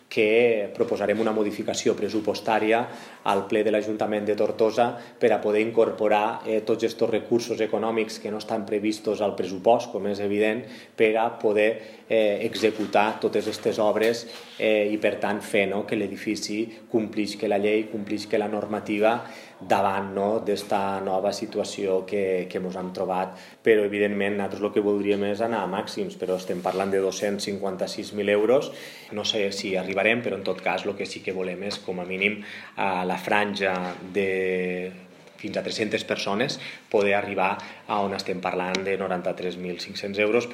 Les obres d’adequació poden suposar una inversió de fins a 256.000 euros per condicionar la sala per a una capacitat màxima de 372 espectadors. L’Alcalde ha explicat que s’haurà de fer una modificació pressupostària …